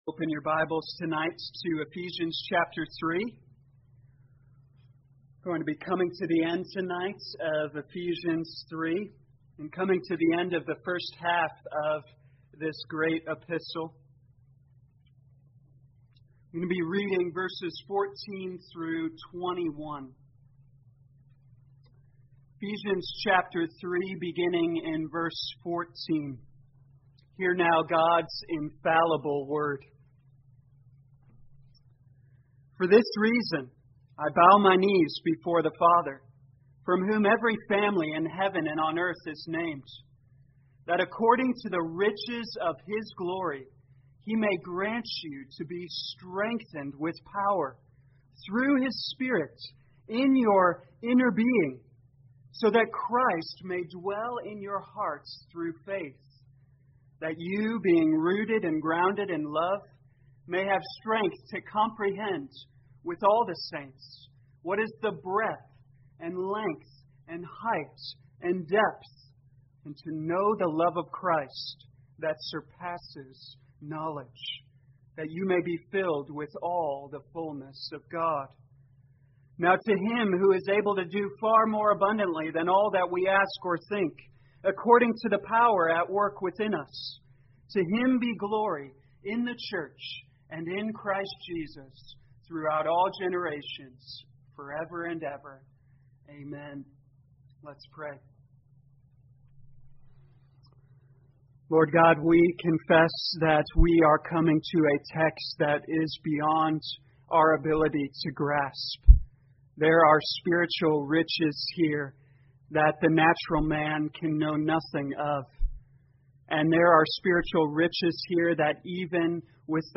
2020 Ephesians Evening Service Download